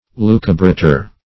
Search Result for " lucubrator" : The Collaborative International Dictionary of English v.0.48: Lucubrator \Lu"cu*bra`tor\, n. One who studies by night; also, one who produces lucubrations.